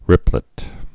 (rĭplĭt)